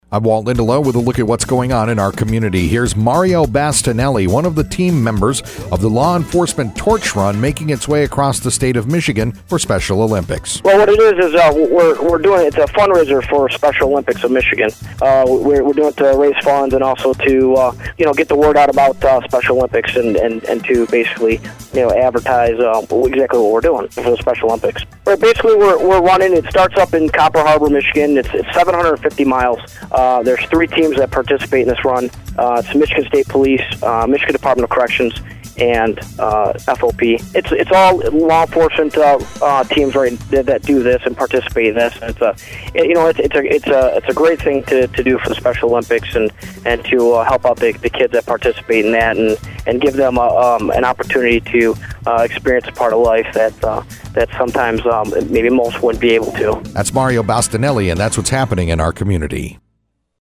RUNDiscussion with one of the running team with the Special Olympics Law Enforcement Torch Run as to why he takes part in the annual event.